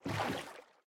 paddle_water2.ogg